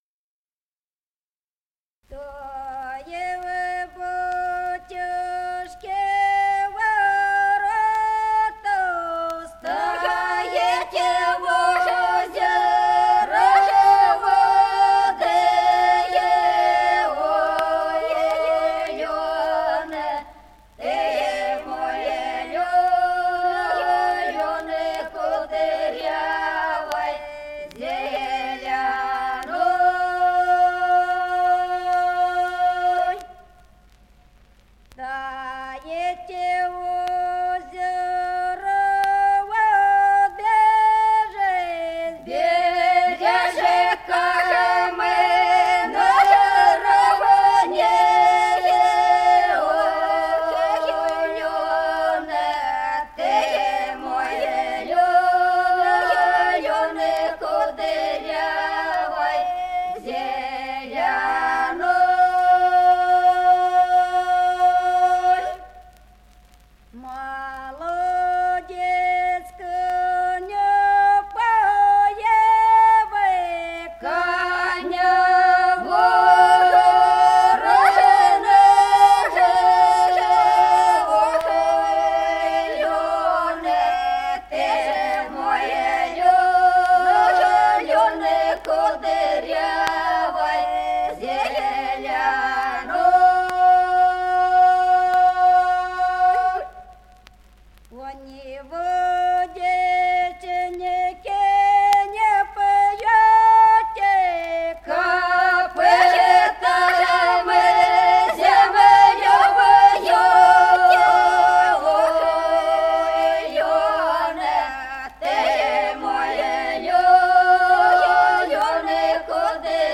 1951 г., с. Остроглядово.